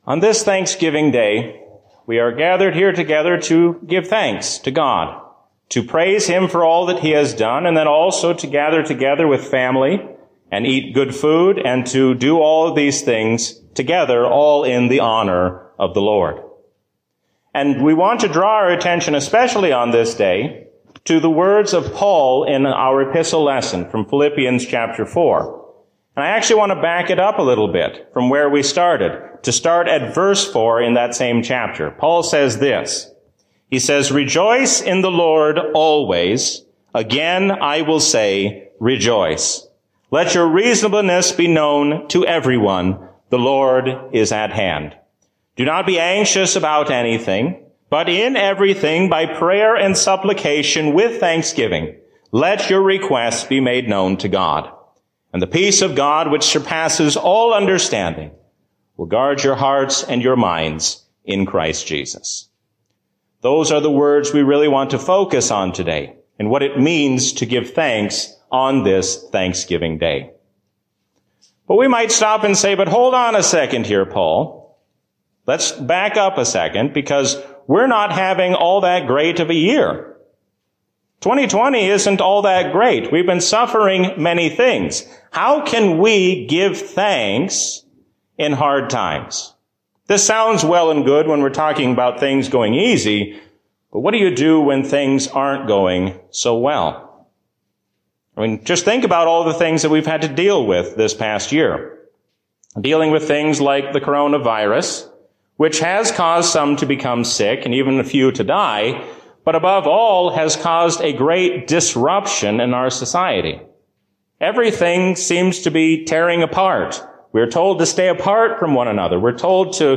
A sermon from the season "Trinity 2023." We can give thanks even when we have nothing at all when we are content in God.